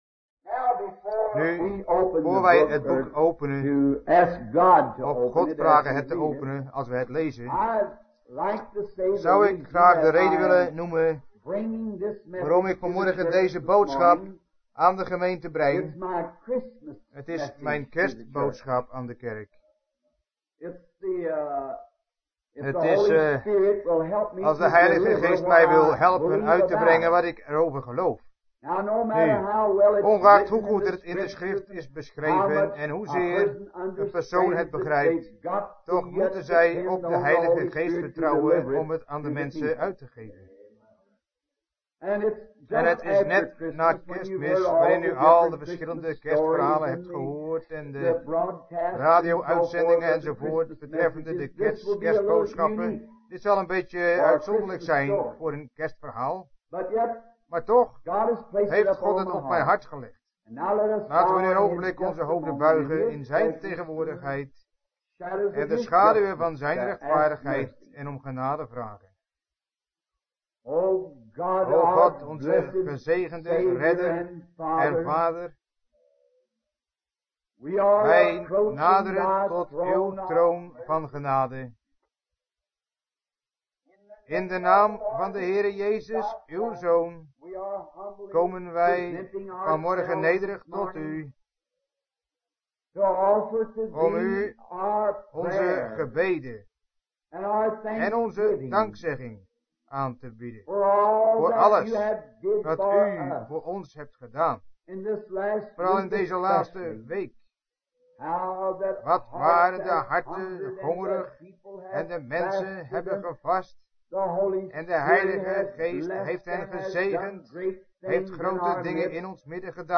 Prediking